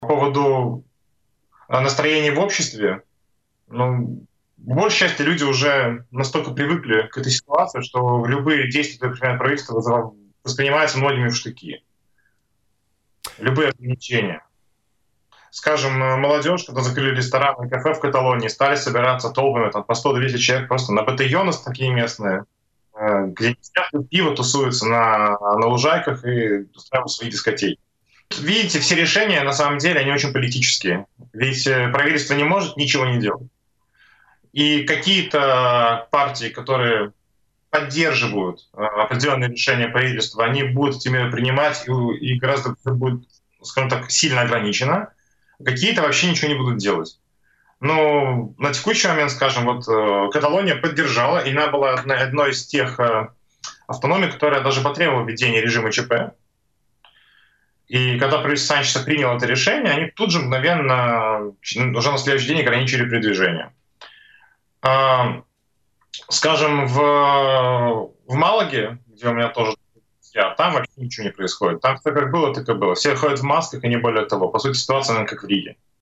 В связи с распространением COVID-19 в Каталонии обсуждается вопрос о запрете на прогулки жителей по выходным. Об этом в интервью радио Baltkom рассказал иммигрант из Латвии